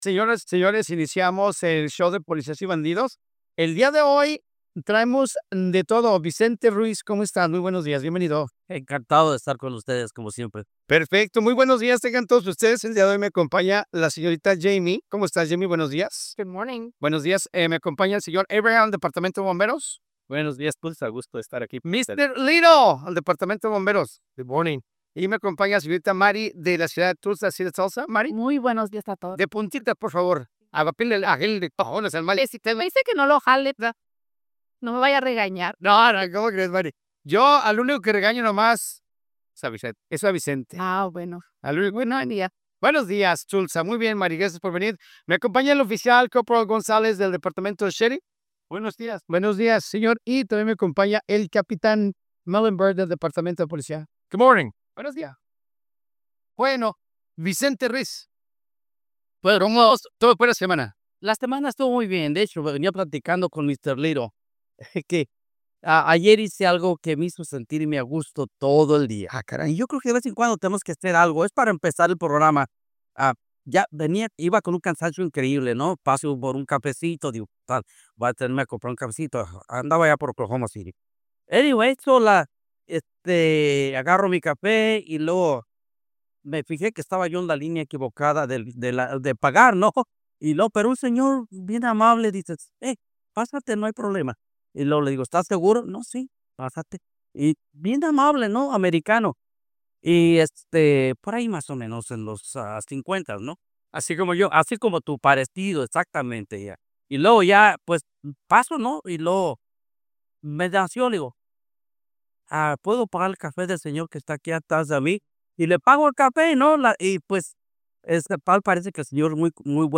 La violencia doméstica volvió a colocarse en el centro de la conversación pública tras un caso reciente que terminó en tragedia, tema principal abordado en el programa Polecias y Bandidos con la participación de autoridades locales y representantes comunitarios.